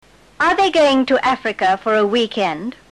Recuerda que a partir de esta lección los audios son más veloces.